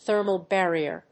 アクセントthérmal bárrier